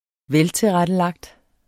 Udtale [ -teˌʁadəlɑgd ]